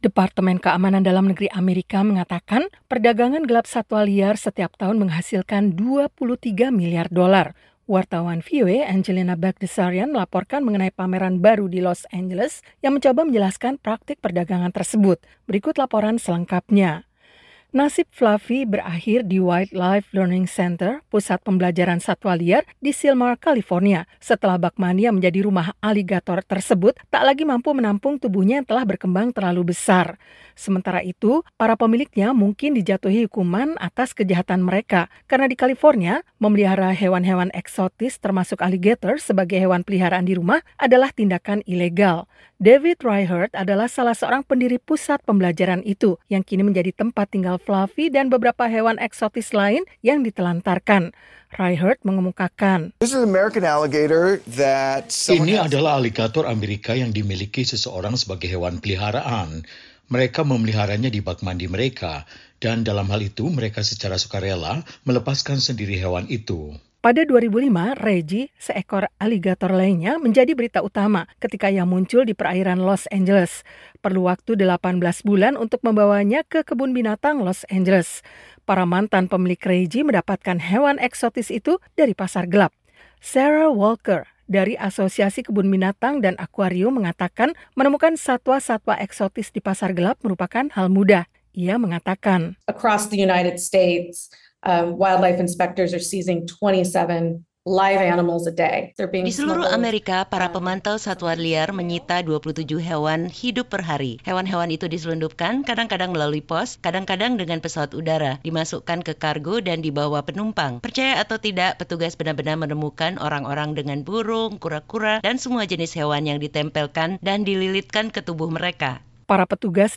Los Angeles (VOA) —